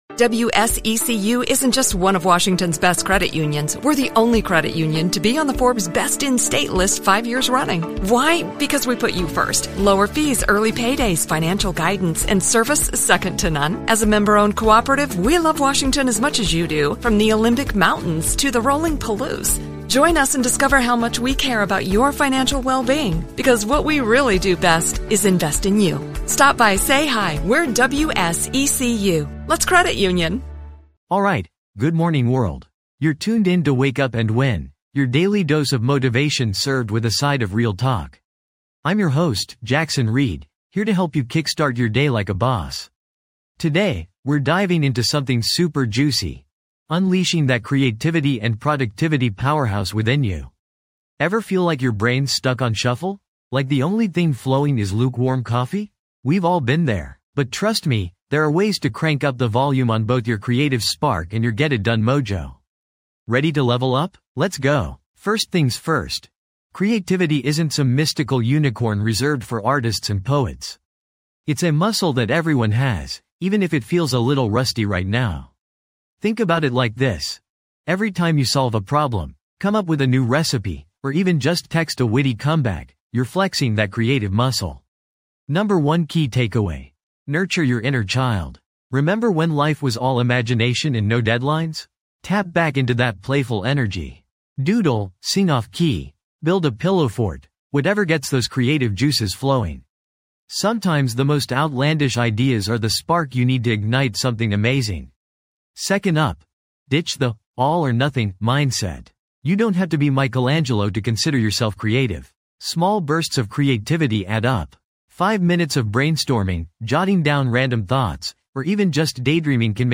Podcast Category: Self-Improvement, Motivation, Inspirational Talks
This podcast is created with the help of advanced AI to deliver thoughtful affirmations and positive messages just for you.